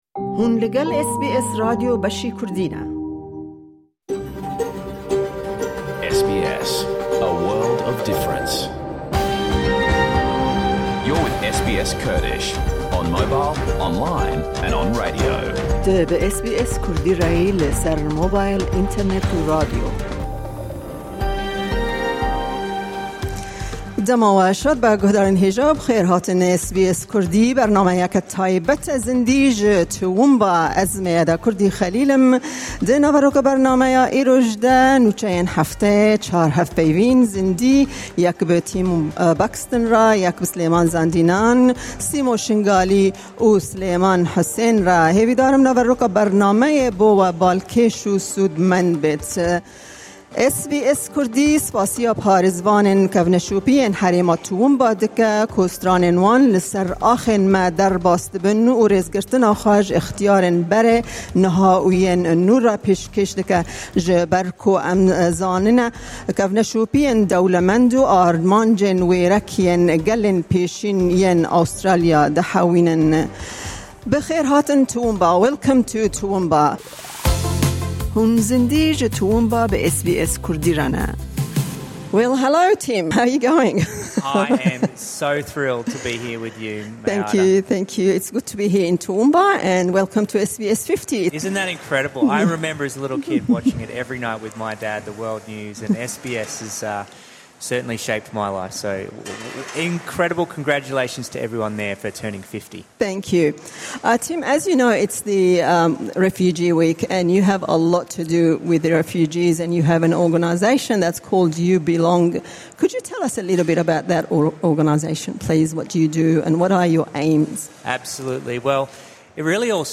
Ji bo SBS50 em çûn Toowoomba, Queensland da ku em bernameyeke zindî li dervayî studyo pêkêş bikin.